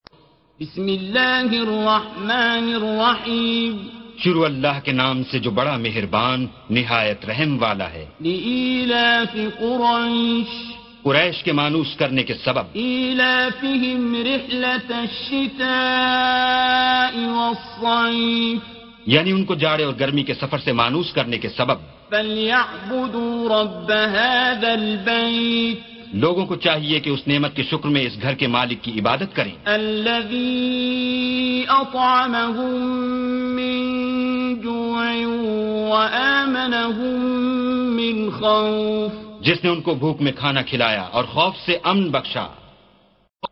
Surah Sequence تتابع السورة Download Surah حمّل السورة Reciting Mutarjamah Translation Audio for 106. Surah Quraish سورة قريش N.B *Surah Includes Al-Basmalah Reciters Sequents تتابع التلاوات Reciters Repeats تكرار التلاوات